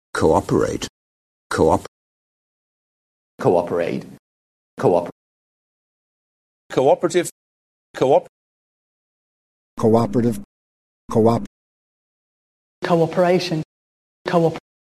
In native pronunciation, coop- is two syllables, and the transition between them is a w type sound.
Here are native cooperate, cooperative and cooperation, each word followed by its beginning:
coop_natives.mp3